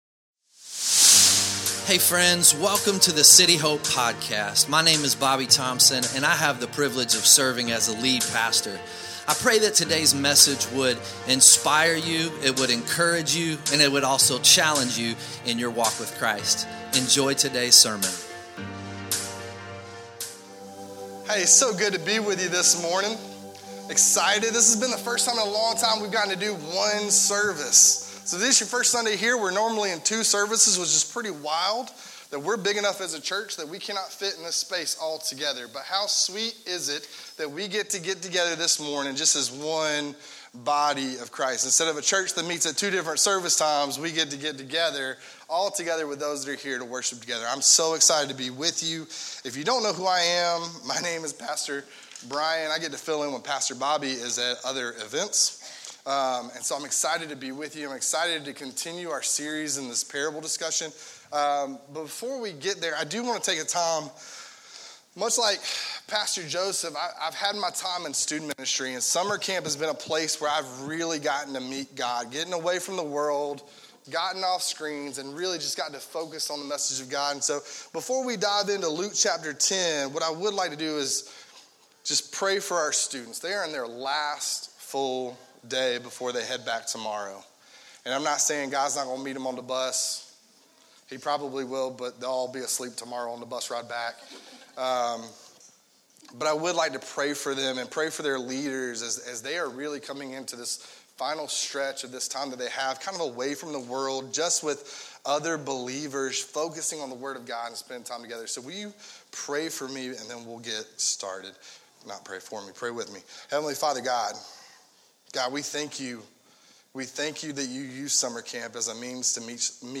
2025 Sunday Morning Because God first loved us